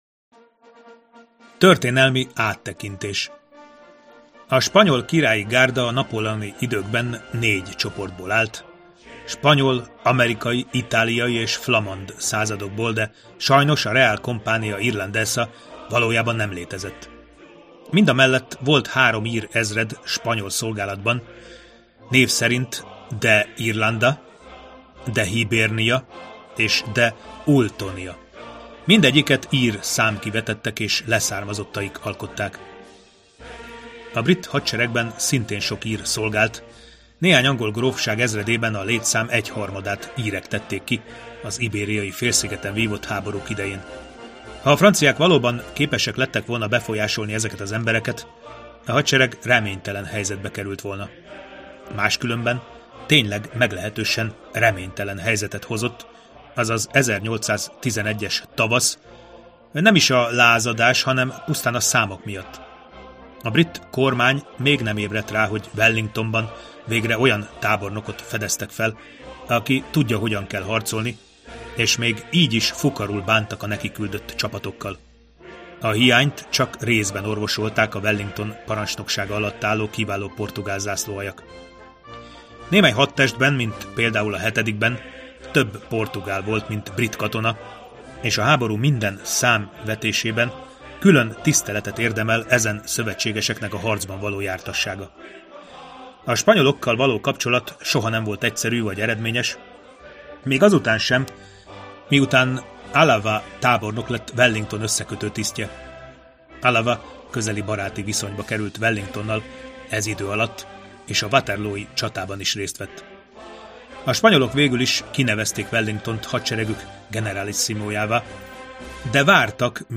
Hangoskönyv